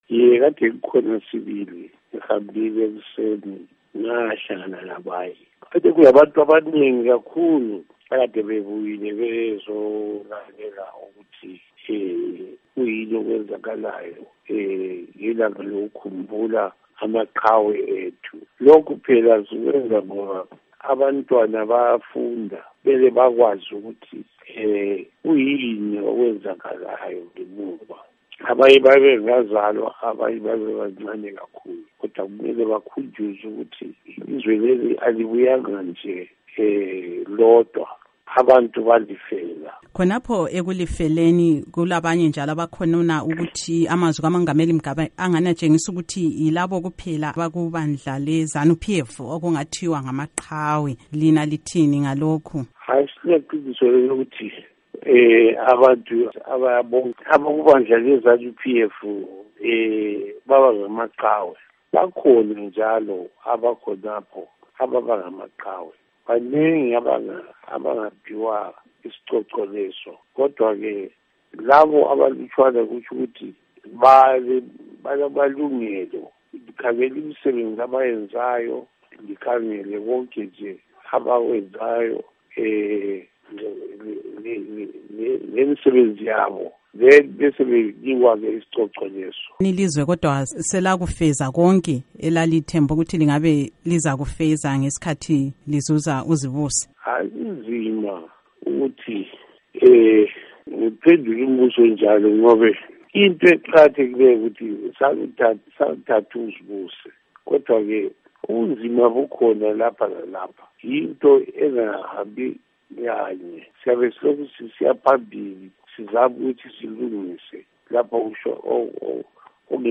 Ingxoxo LoRetired Colonel Tshinga Dube